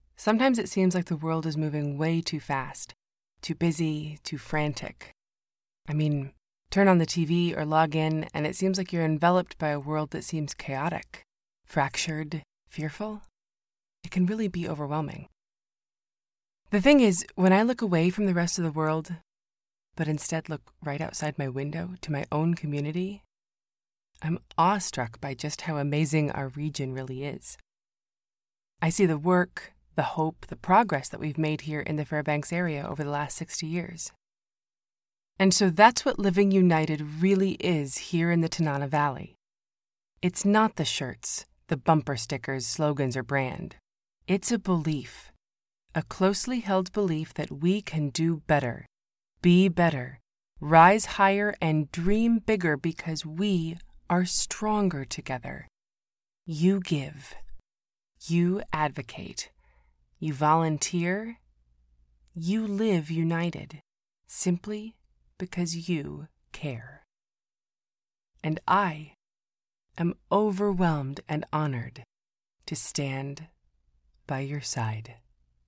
United Way of Tanana Valley TV Ad
My voice is warm and comforting, relatable, humorous, and authoritative. As a natural alto I am very comfortable in low, smokey and intimate ranges.